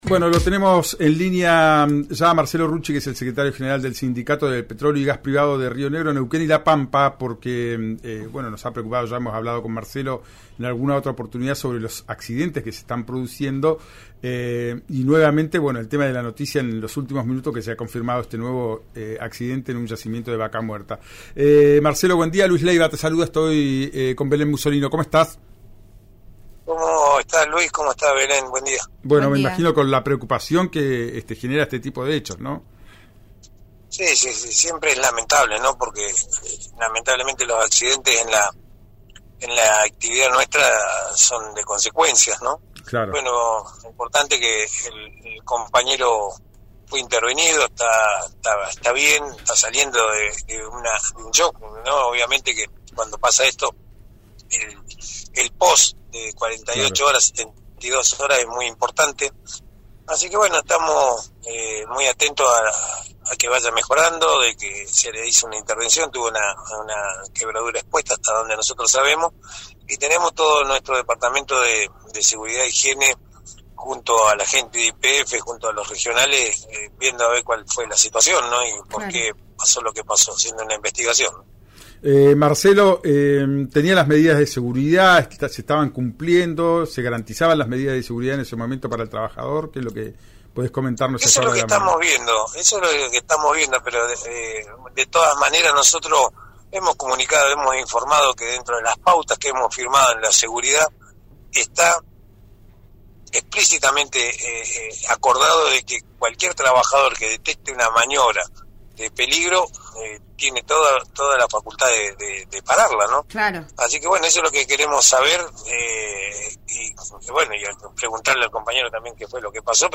habló con RÍO NEGRO RADIO, tras el incidente de un petrolero en un yacimiento de YPF en Vaca Muerta.